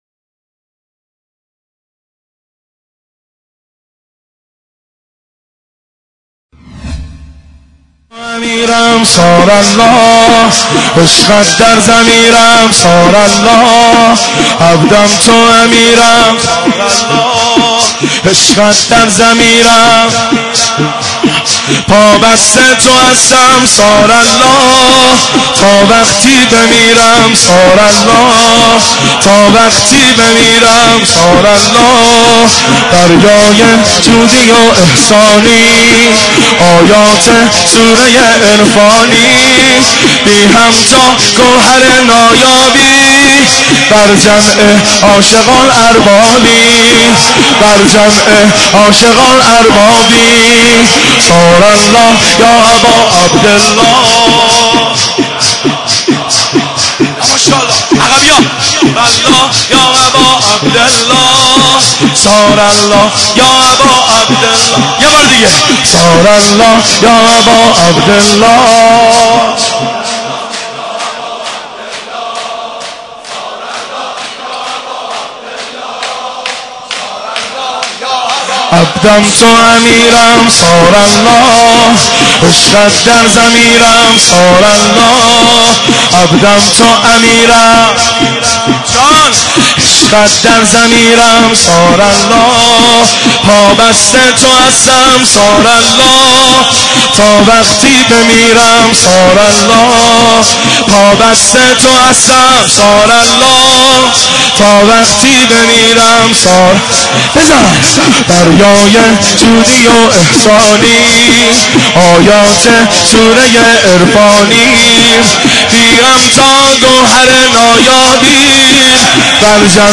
شور شب سوم محرم الحرام 1389
هیئت بین الحرمین طهران